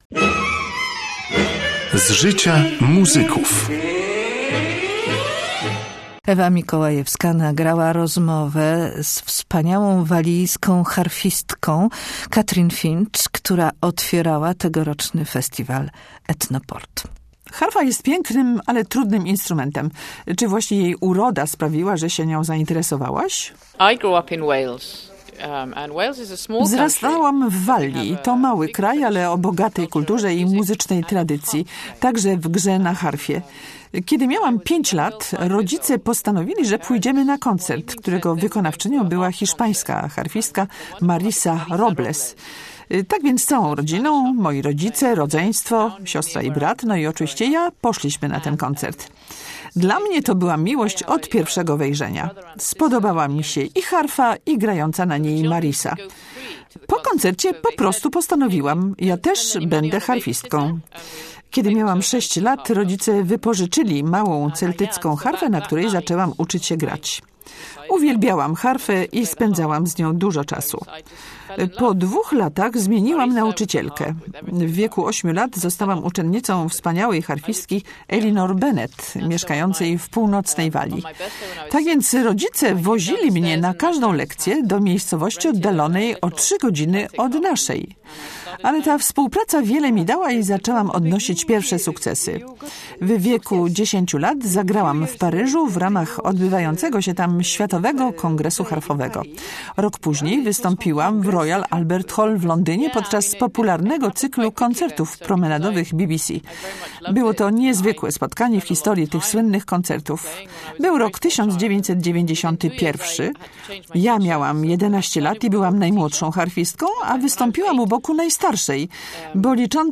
08yds3jcxb1mo8c__catrin_finch_wywiad.mp3